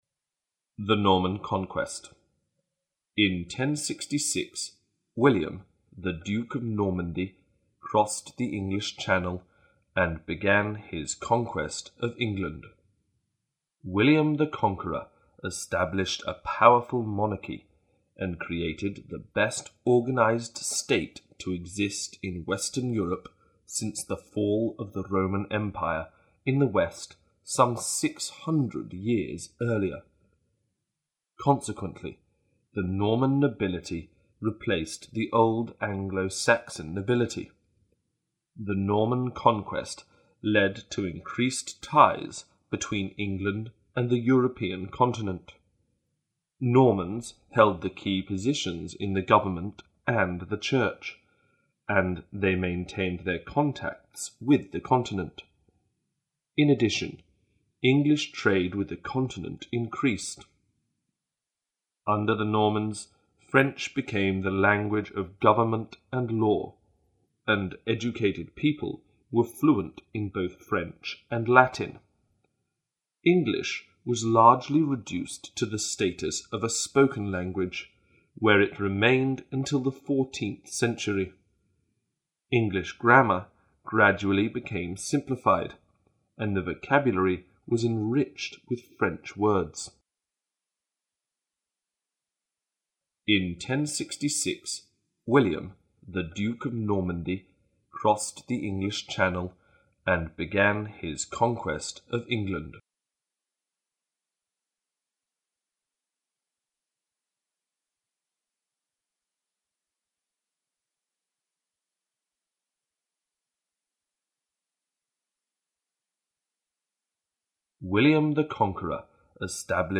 During the first reading, which will be done at normal speed, listen and try to understand the meaning. For the second and third readings, the passage will be read sentence by sentence, or phrase by phrase, with intervals of 15 seconds. The last reading will be done at normal speed again and during this time you Should check your work.